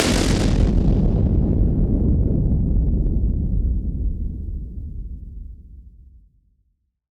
BF_SynthBomb_B-03.wav